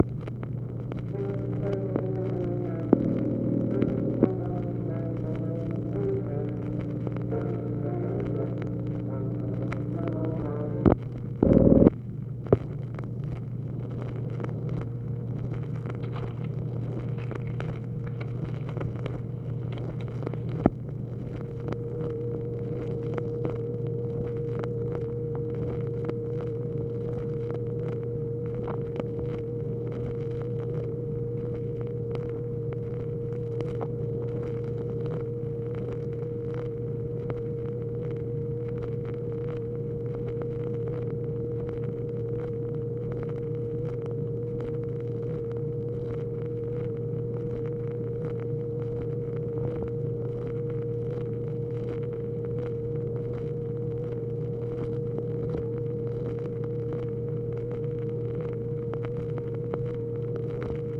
OFFICE NOISE, March 13, 1965
Secret White House Tapes | Lyndon B. Johnson Presidency